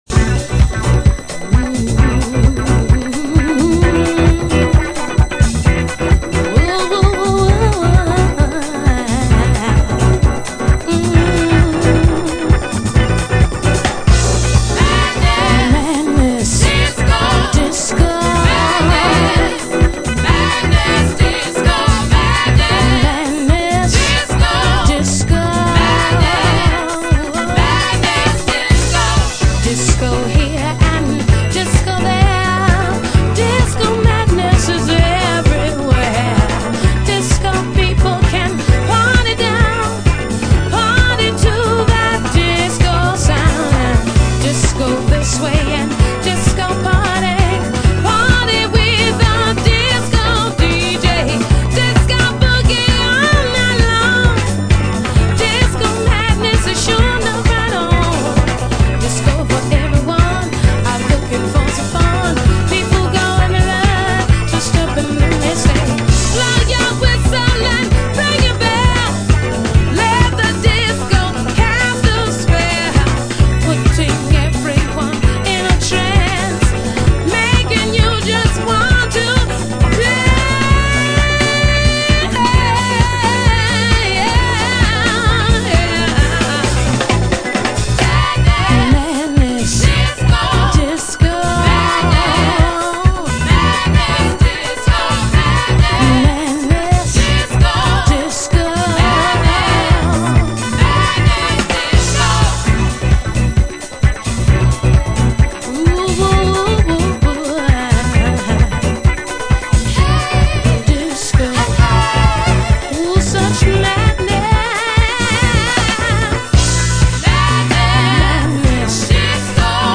人気ディスコ2タイトルをカップリングしたお買い得12インチ復刻盤！！